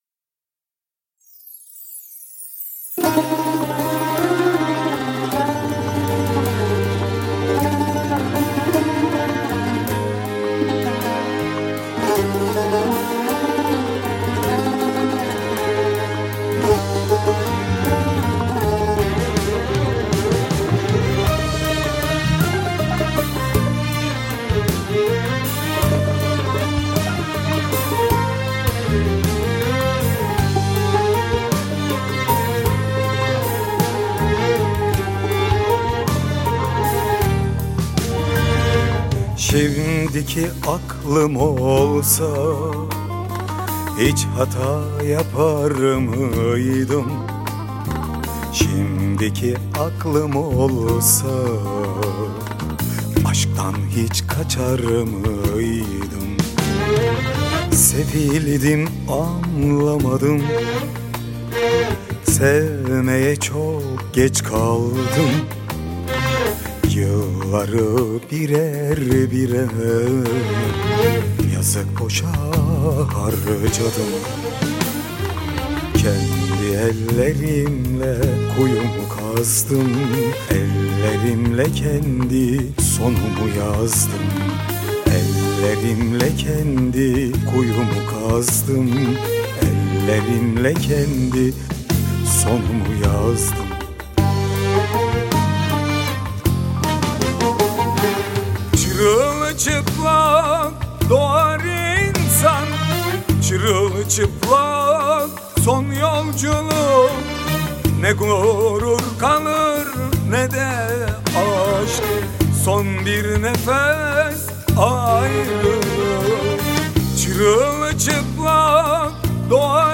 Eser Şekli : Pop Fantazi